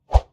Close Combat Swing Sound 63.wav